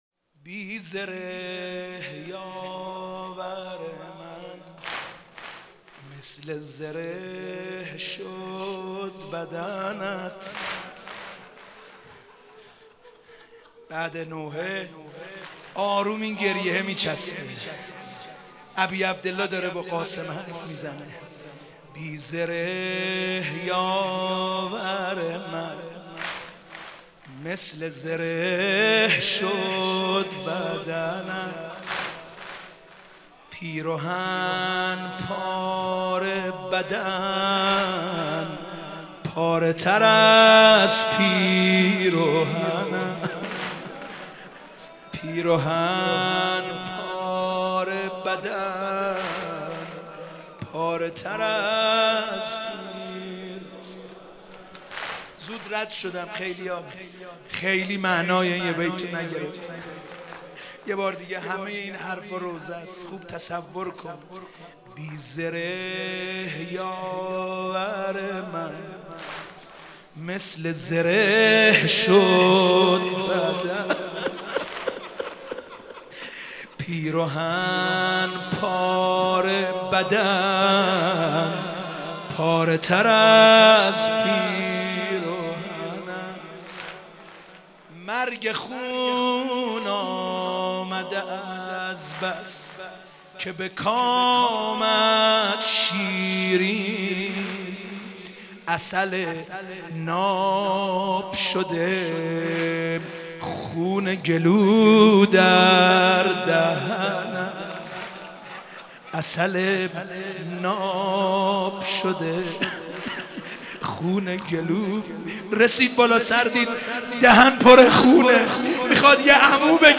شب ششم محرم97 - مسجد امیر - واحد - بی زره یاور من